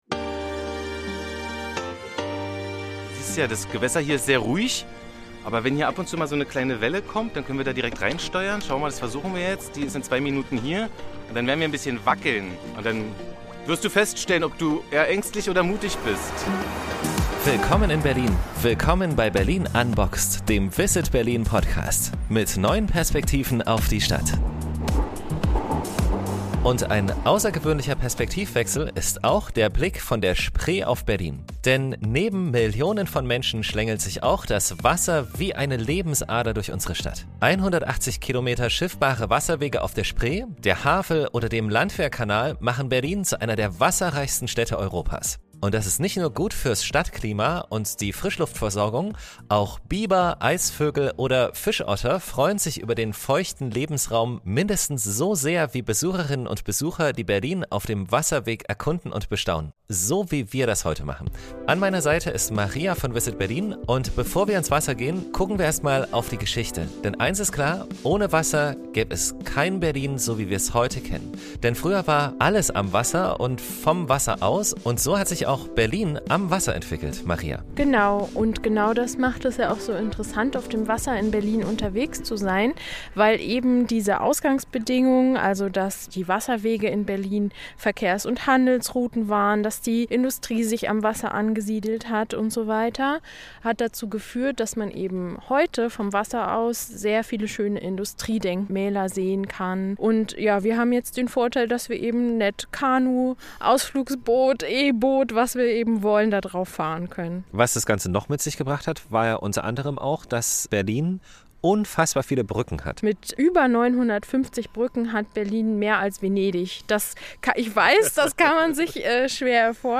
In dieser Folge geht’s ab auf die Spree! Wir schnappen uns Schwimmwesten und Paddel und machen eine Kanutour